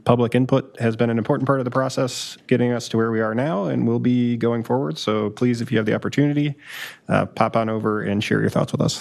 Here’s councilmember Chris Burns.